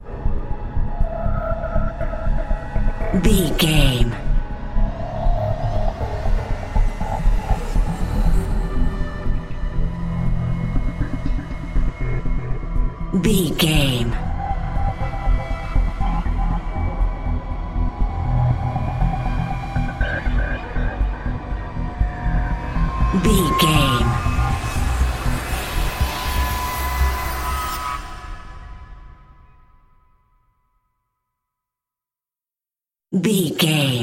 Thriller
Aeolian/Minor
E♭
Slow
synthesiser
ominous
dark
suspense
haunting
creepy